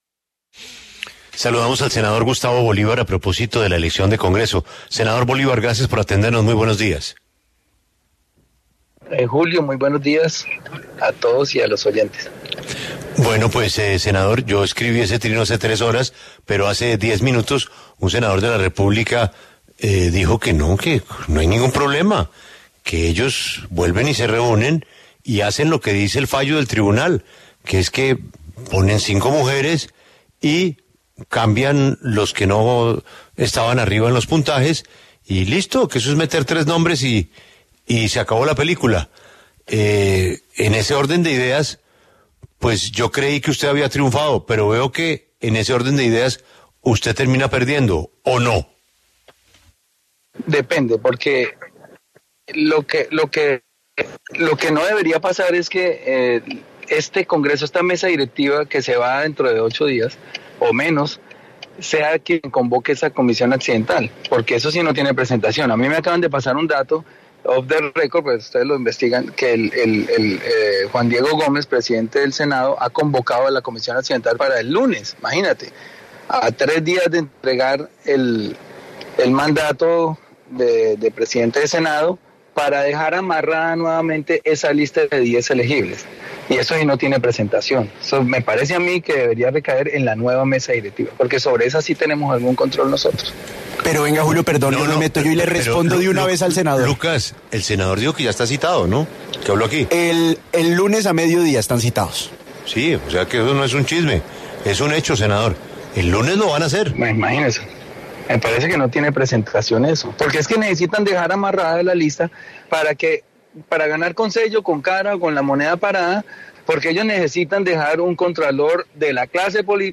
En diálogo con La W, el senador Gustavo Bolívar se pronunció a la orden del Tribunal de Cundinamarca al Congreso de la República de rehacer la lista de aspirantes a contralor.